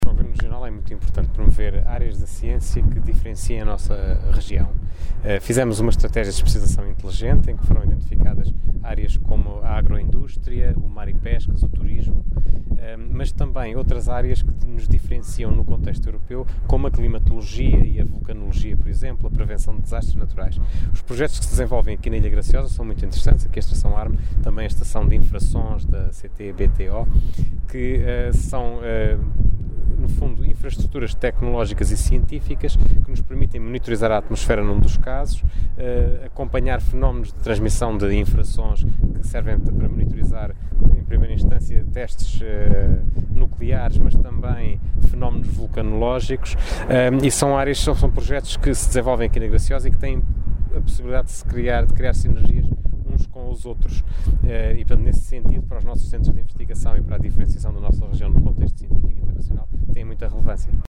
“É muito importante desenvolver áreas da ciência que diferenciem a nossa Região no contexto científico internacional, como a Climatologia e a Vulcanologia”, afirmou Fausto Brito e Abreu, em declarações no final das duas visitas.